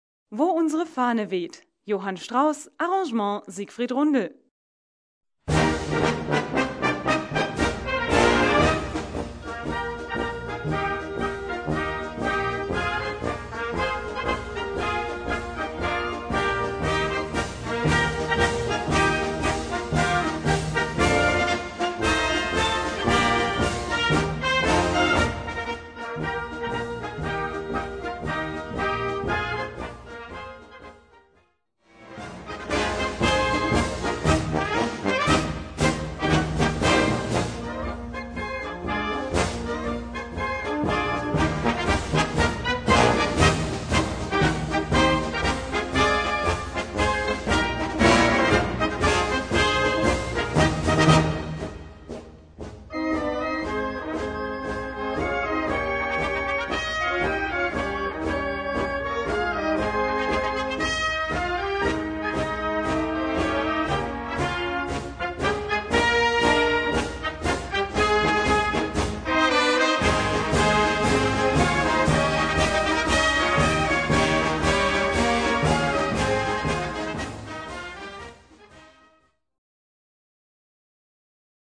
Gattung: Marsch
Besetzung: Blasorchester
Zündender Marsch